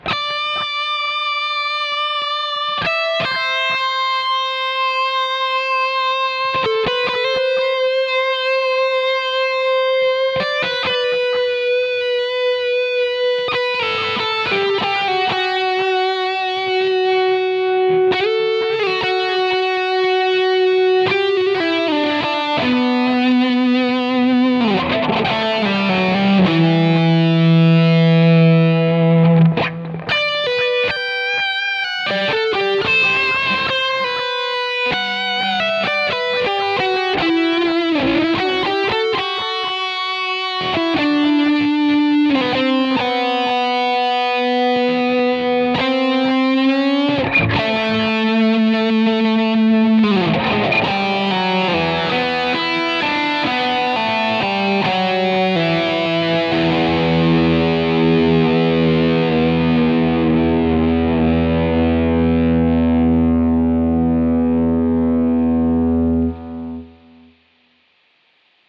finally got around to testing out some real pedals with my podxt. got some really pleasant results with my Sobbat DriveBreaker I... basically a souped-up handmade japanese tubescreamer... same chip, but a lot more distortion, as well as better clean boost capabilities and separate bass and treble controls.
for this sound, i used the highway 100 amp, and recorded with three separate cabs... the 4x12 v30, 4x12 green 25, and 1x12 blackface. ibanez 540, bridge humbucker. i'll post settings later, but they won't do you much good without the pedal... pedal settings are:
LeadDrivebreaker (4x12 Green25s) 1.32 MB (1:08)   as above, with 4x12 green 25s cab